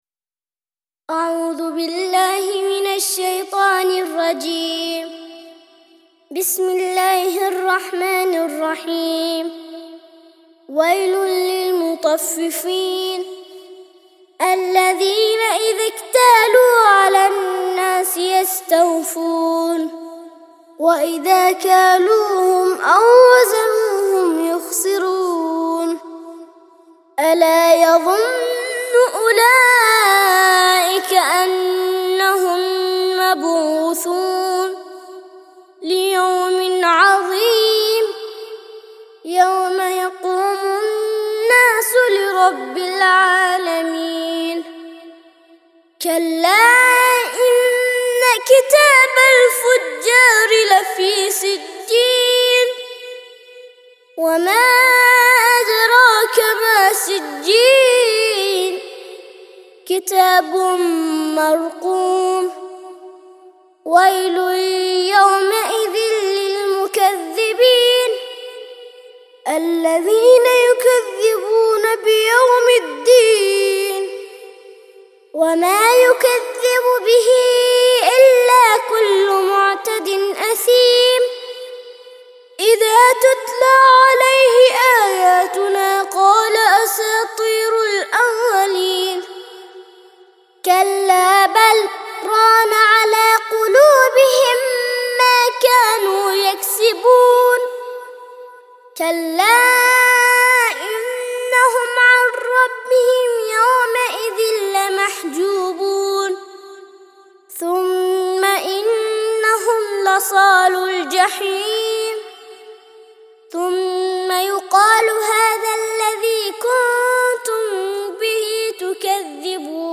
83- سورة المطففين - ترتيل سورة المطففين للأطفال لحفظ الملف في مجلد خاص اضغط بالزر الأيمن هنا ثم اختر (حفظ الهدف باسم - Save Target As) واختر المكان المناسب